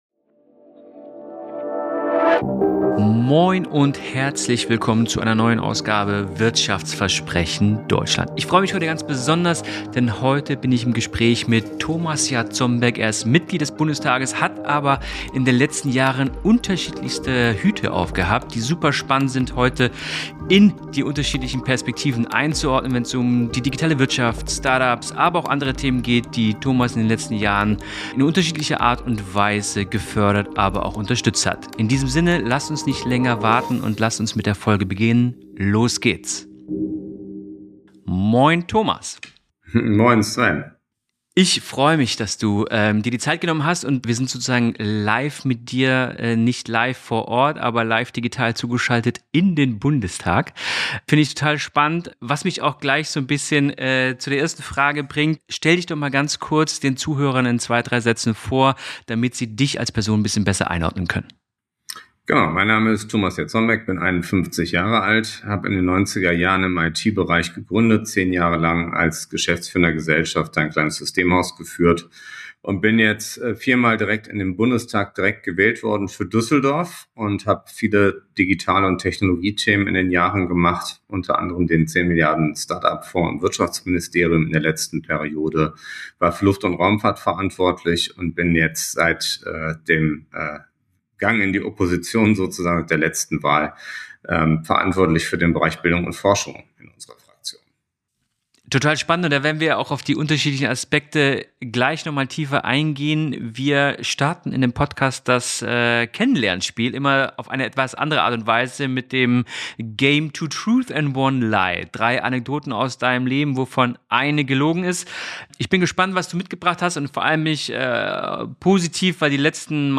Ein spannendes Gespräch über die Zukunft der deutschen Wirtschaft, das Startup-Ökosystem und die Rolle der Technologie in einer sich ständig wandelnden Welt.